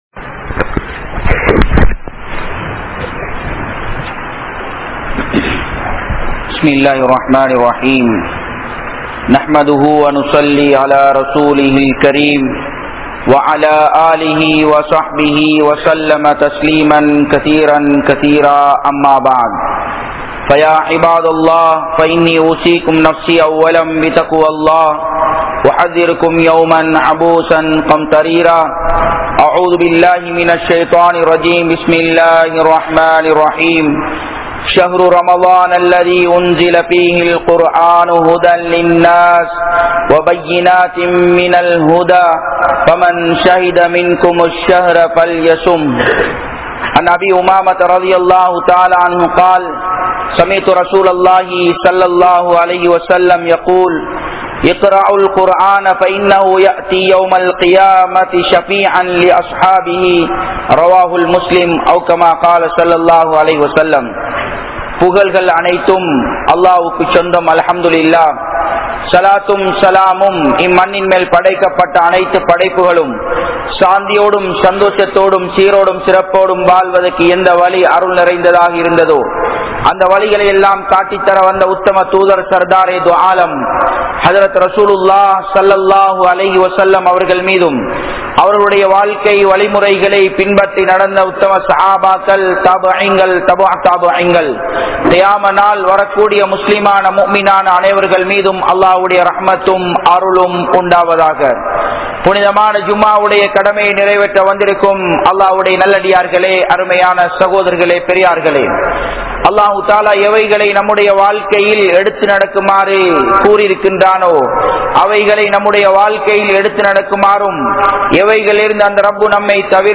Al Quran Yaarukku Vali Kaattum? (அல்குர்ஆன் யாருக்கு வழி காட்டும்?) | Audio Bayans | All Ceylon Muslim Youth Community | Addalaichenai